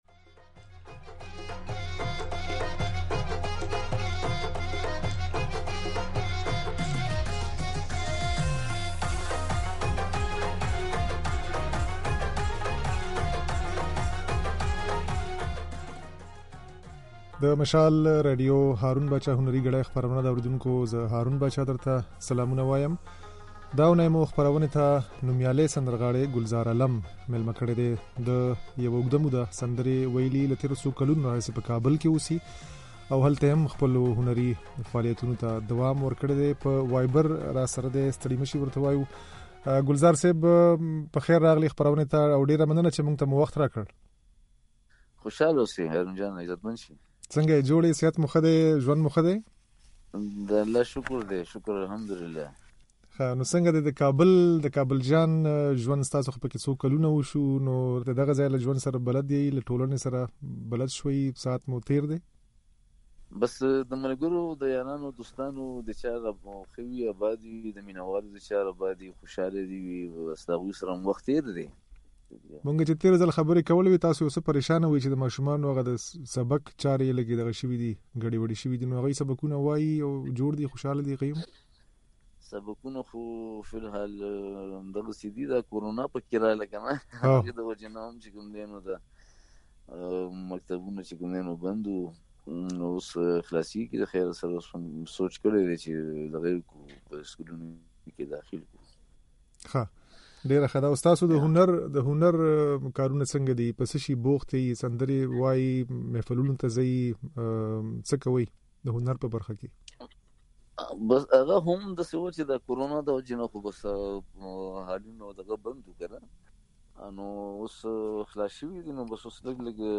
دا اوونۍ مو "هارون باچا هنري ګړۍ" خپرونې ته نوميالی سندرغاړی ګلزار عالم مېلمه کړی وو.